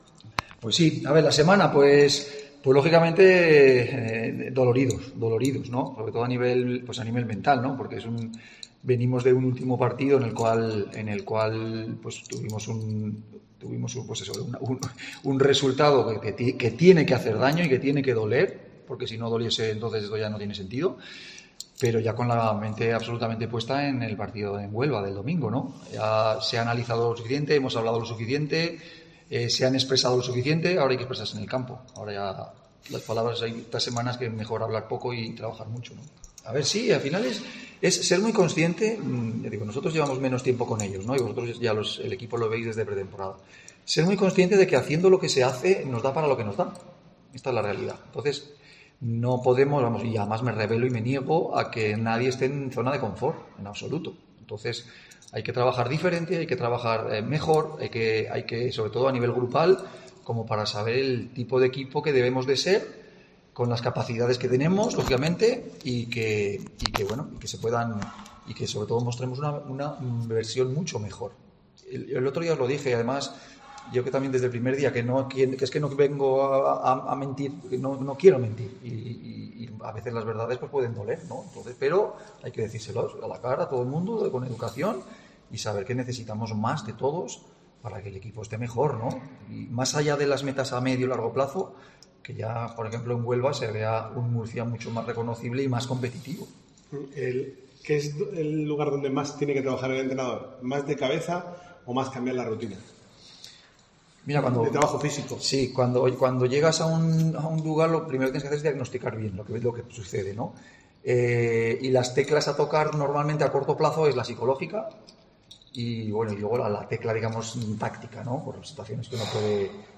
Pablo Alfaro atendió a los medios de comunicación en la rueda de prensa previa a la jornada 15, en la que el Real Murcia se enfrentará al Recreativo de Huelva este domingo 3 de diciembre en el Nuevo Colombino.